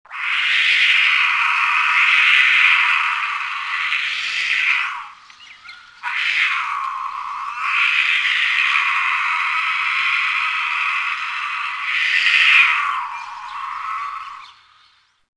cougar.mp3